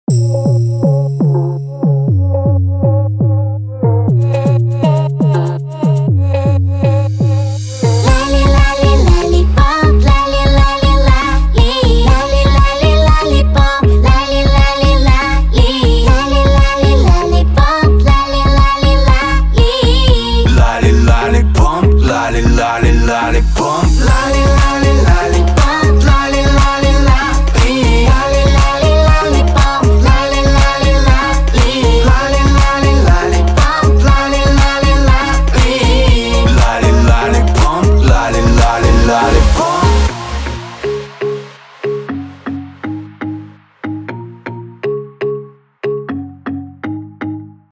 • Качество: 320, Stereo
женский вокал
dance
дуэт
club
красивый женский голос
женский и мужской вокал